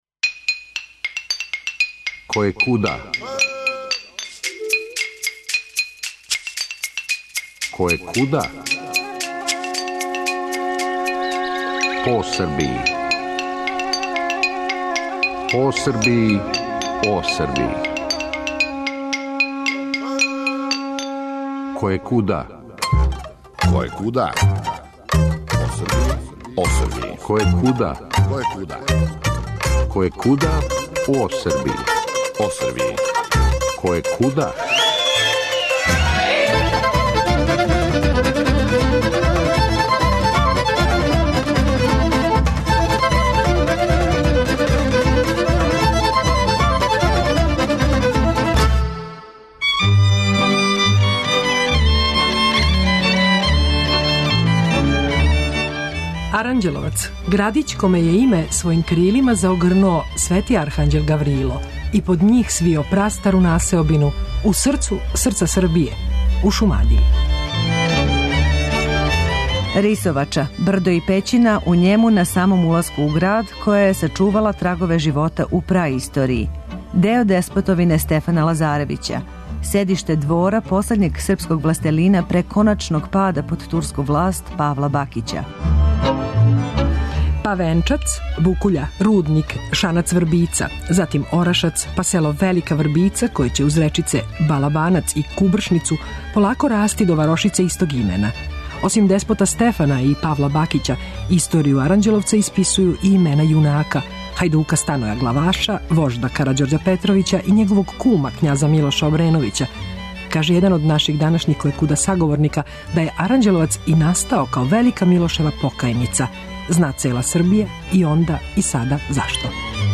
Много је прикљученија у овој причи, па ће је, којекуде, вама казивати двојица Аранђеловчана.